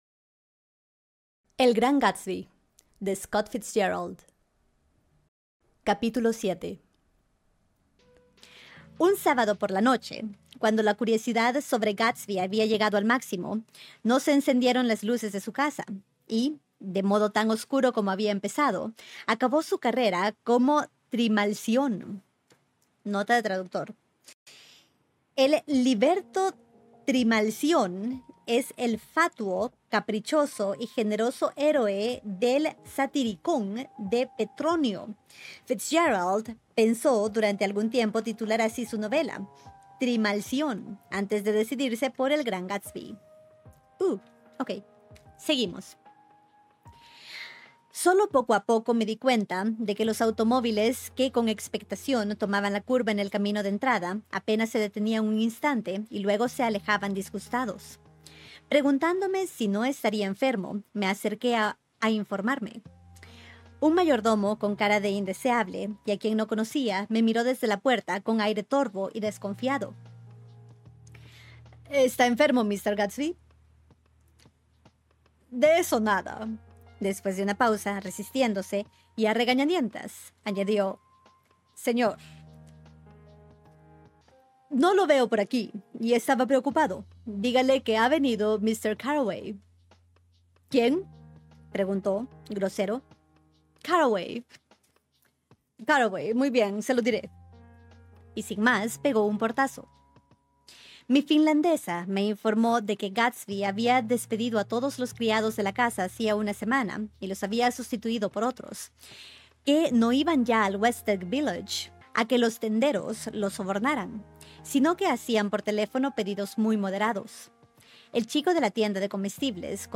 Clásicos al oído es tu puerta de entrada al mundo de los grandes clásicos de la literatura.¿Siempre quisiste leer las historias originales de los Hermanos Grimm o sumergirte en novelas como Frankenstein, pero nunca encontraste el momento? ¡Este es tu espacio!En Clásicos al oído, cada obra es leída en capítulos que puedes disfrutar mientras manejas, cocinas o simplemente necesitas un momento para ti.